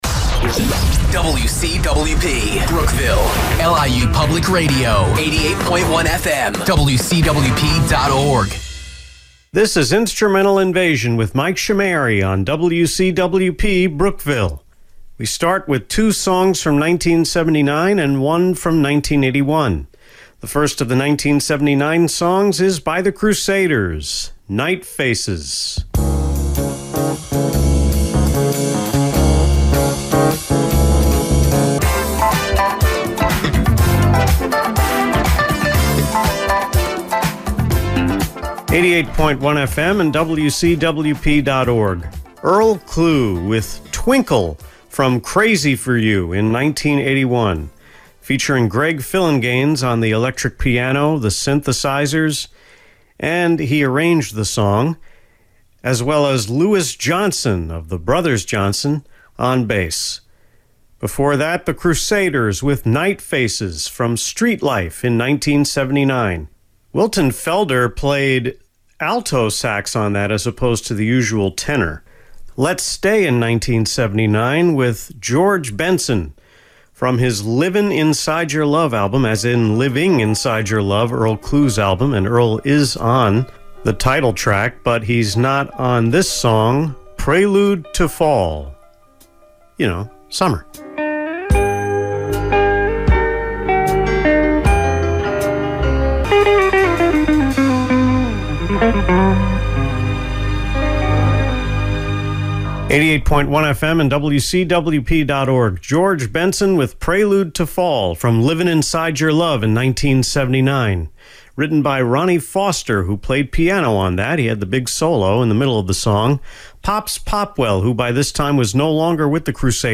Work on the playlist began on May 2 with annotations coming on the 11th and during the recording session on the 17th.